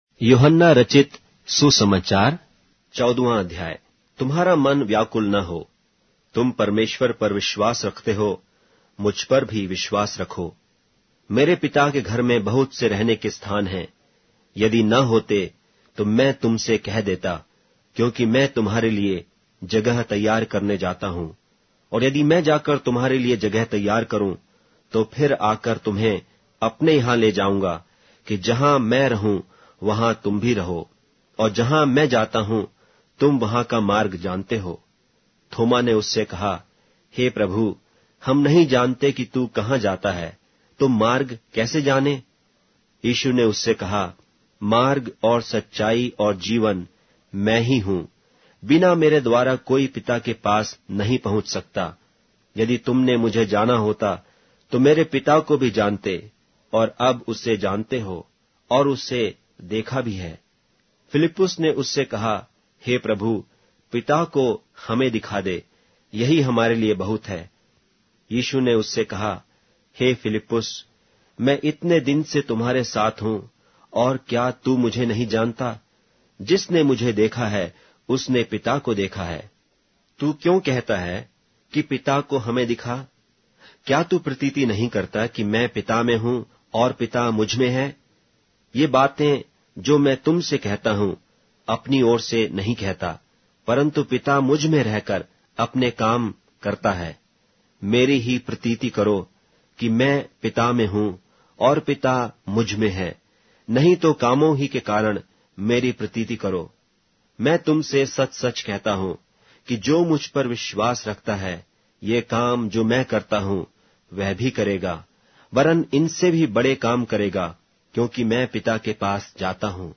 Hindi Audio Bible - John 2 in Ocvkn bible version